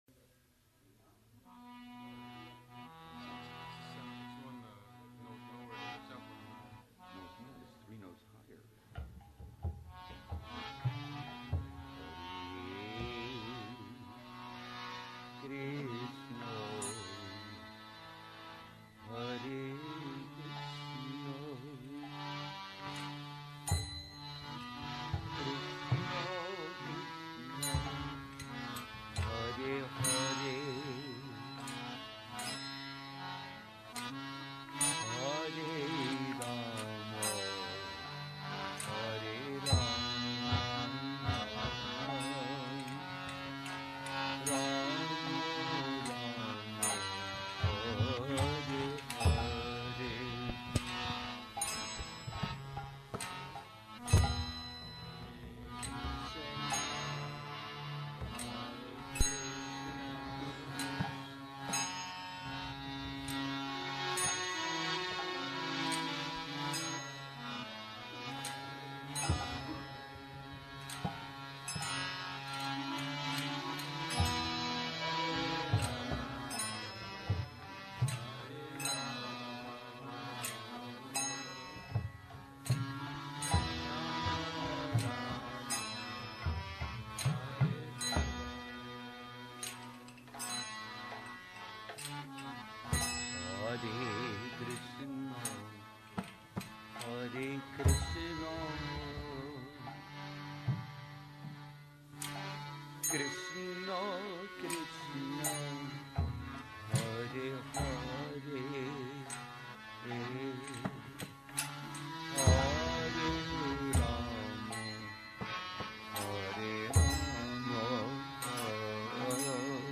temple kirtans